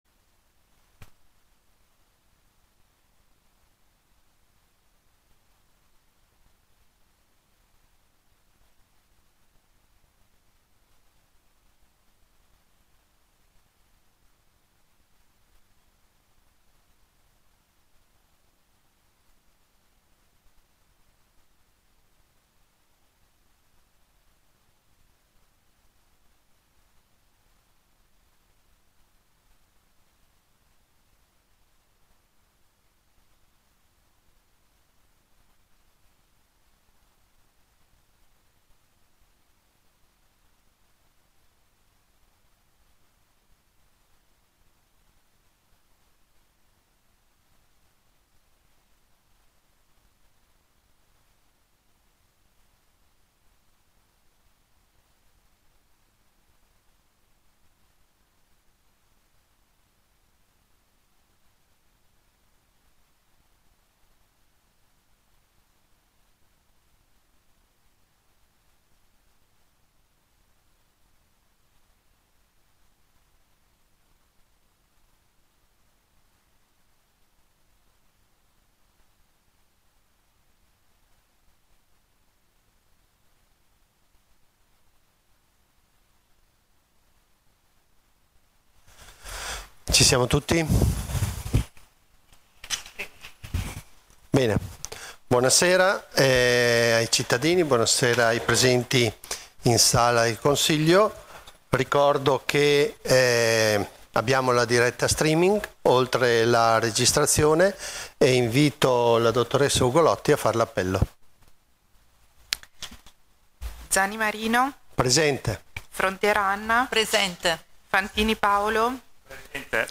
Seduta del consiglio comunale del 17/3/2026